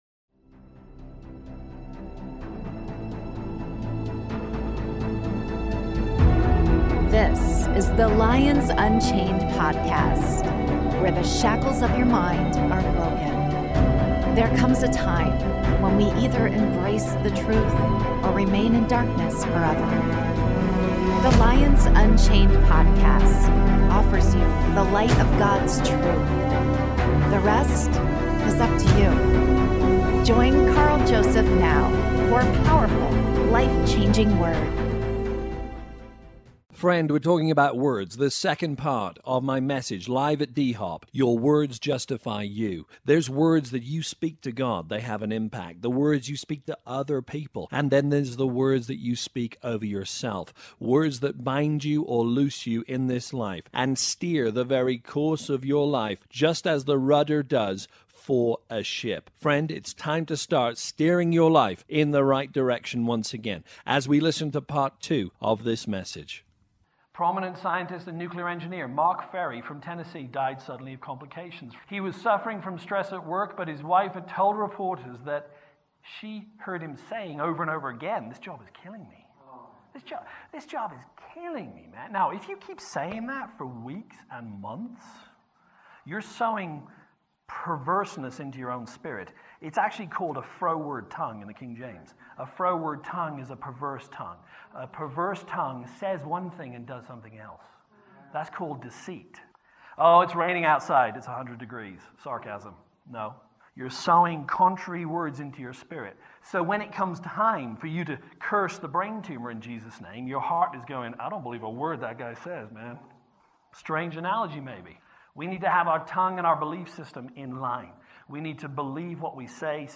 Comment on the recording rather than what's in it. Part 2 (LIVE)